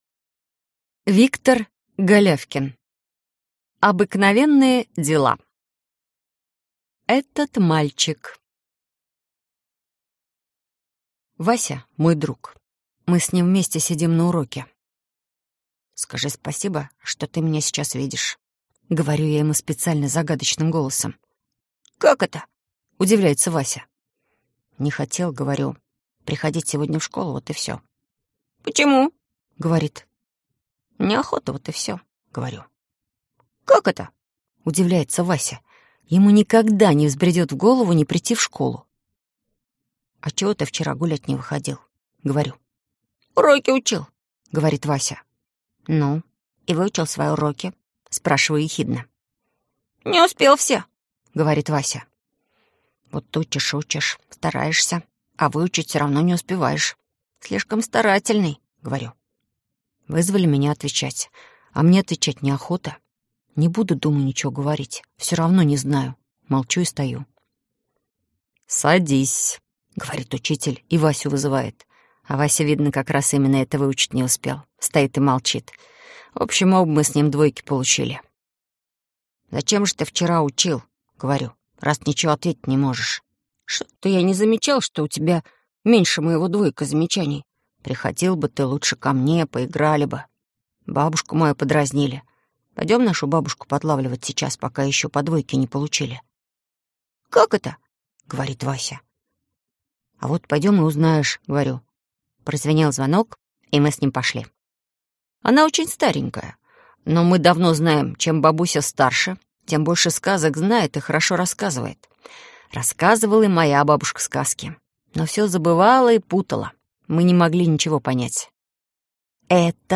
Аудиокнига Обыкновенные дела | Библиотека аудиокниг